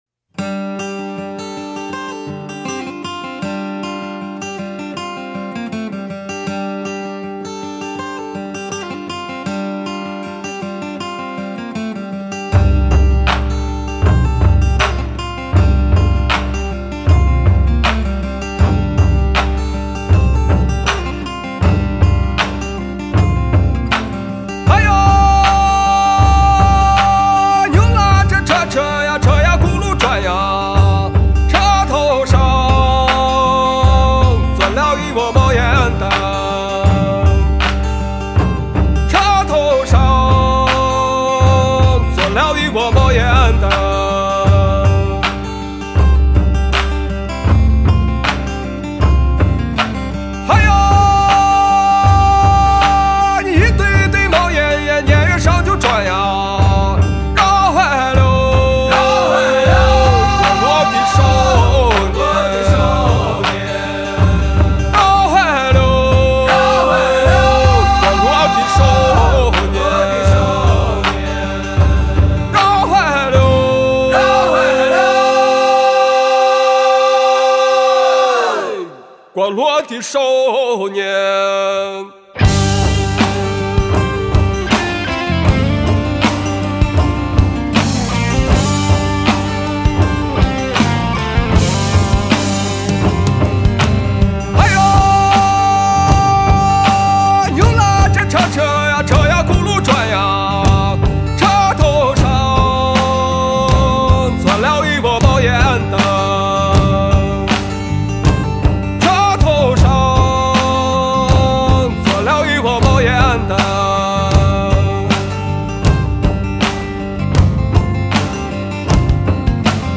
是一支极具地方特色的摇滚乐队
坚持民间和本土 音乐的融合，民乐和现代音乐的融合